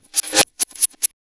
MAD138TAMB-L.wav